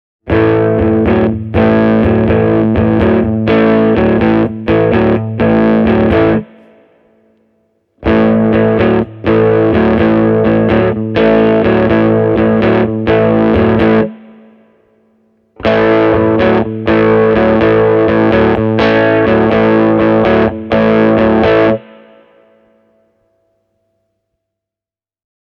Humbuckereita käyttämällä ja täydellä gainilla GVT15-112:n saa juurevaan Blues-säröön. Kompressointi on silloin melko lievää, mikä edesauttaa dynaamista soittamista.
SG – gain täysillä
sg-e28093-full-amp-gain.mp3